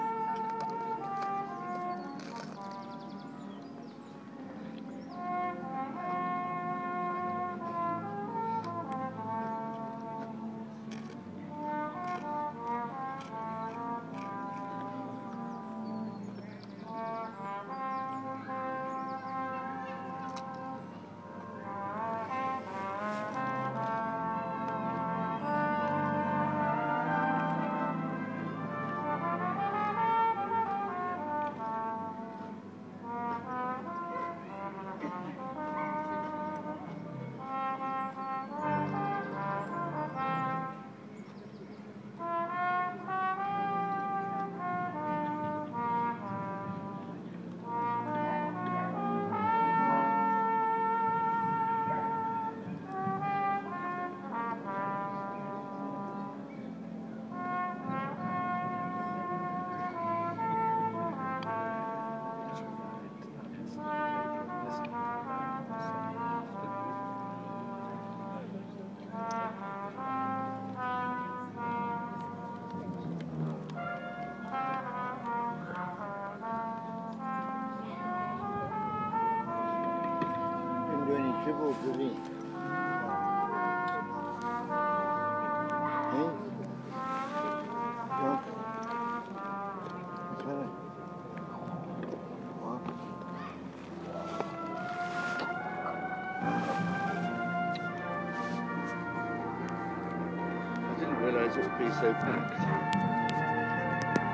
Brass Band and Birdsong Boo (recorded Sunday afternoo)